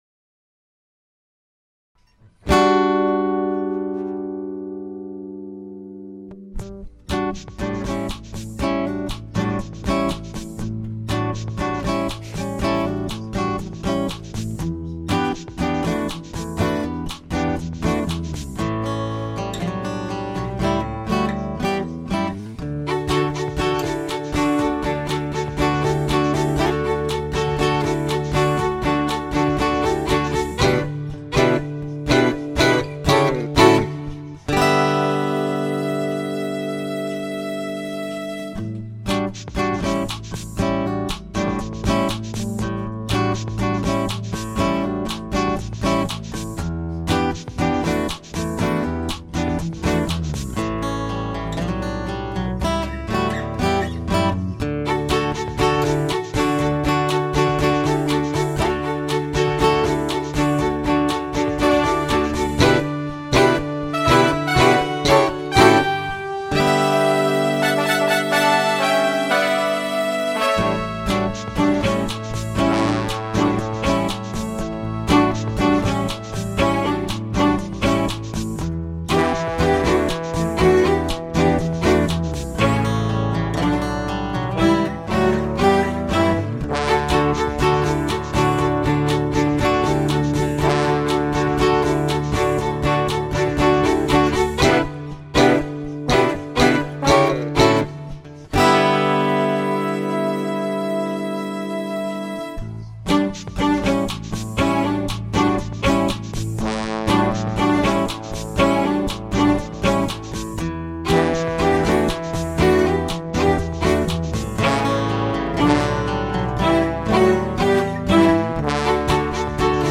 This is a piece I wrote for a planetarium show. Not a great mix but it sounded cool in a big theater.